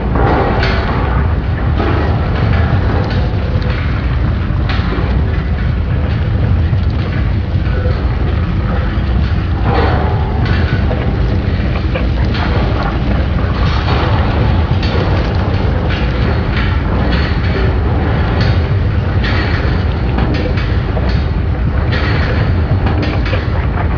grinder.wav